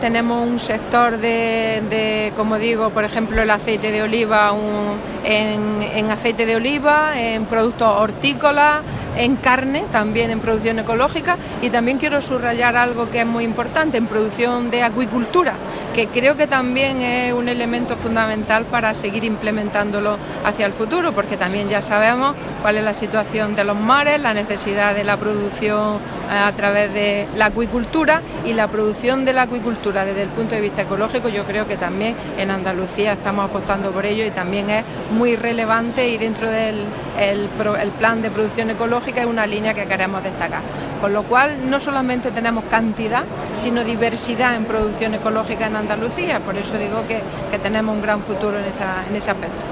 Declaraciones de Carmen Ortiz sobre BioCultura